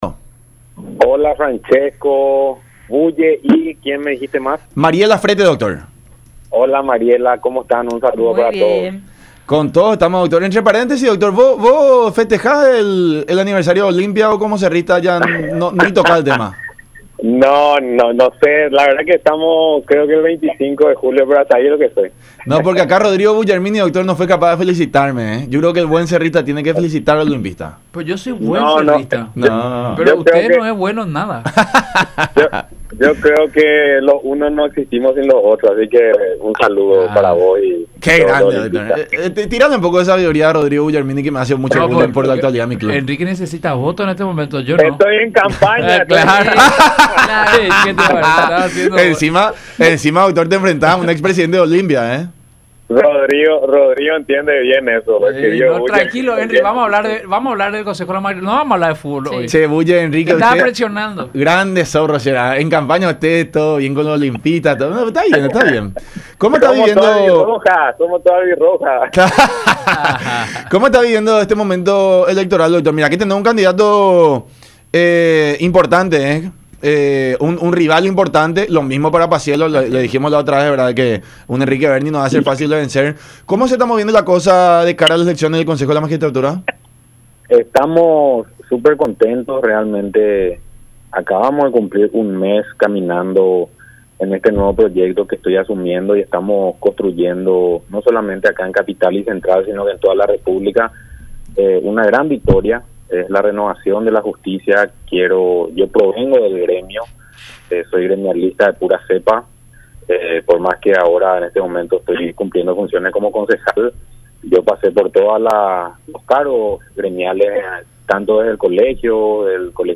en charla con el programa “La Unión Hace La Fuerza” por Unión TV y radio La Unión.